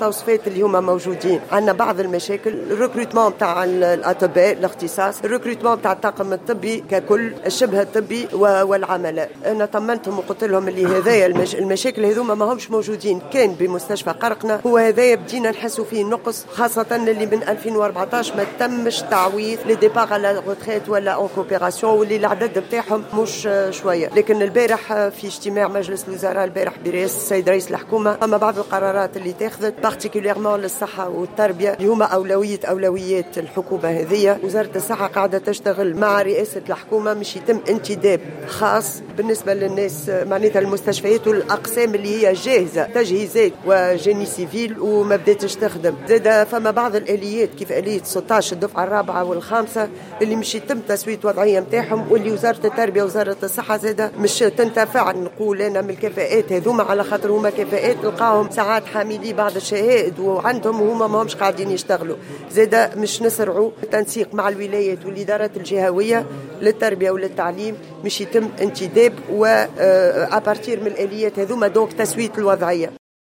وقالت في تصريح لمراسل "الجوهرة اف أم" على هامش ملتقى طبي بصفاقس، إنه ستجري عملية انتداب خاصة في المشاريع الصحية والمستشفيات والأقسام الطبية الجاهزة والتي مازالت دون طاقم طبي وشبه طبي وعملة.